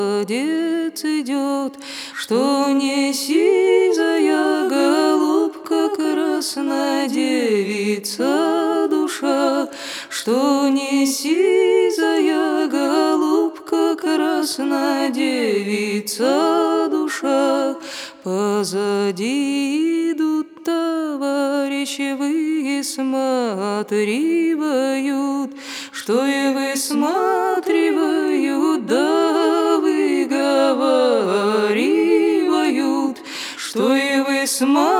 Жанр: Русская поп-музыка / Русские